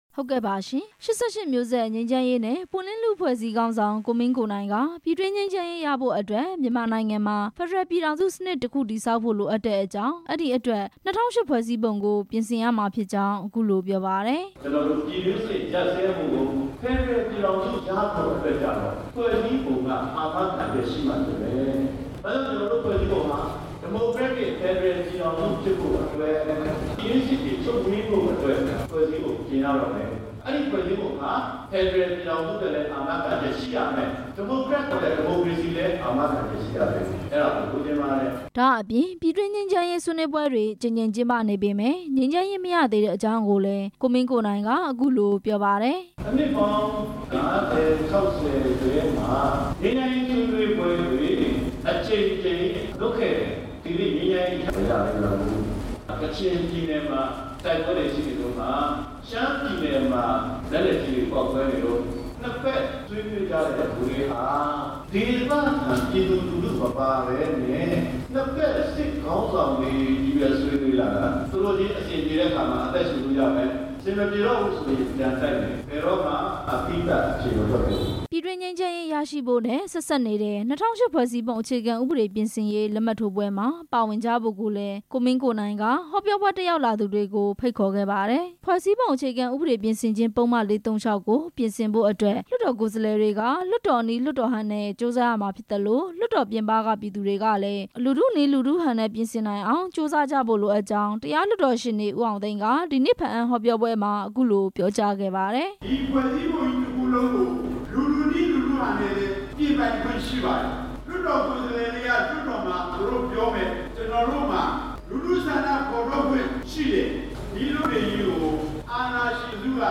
ကရင်ပြည်နယ် ဘားအံမြို့နယ် ကန်သာဦးခန်းမမှာ ဒီနေ့ကျင်းပတဲ့ ပုဒ်မ ၄၃၆ ပြင်ဆင်ရေး လူထုဟောပြောပွဲမှာ ကိုမင်းကိုနိုင်က အခုလိုပြောခဲ့ တာပါ။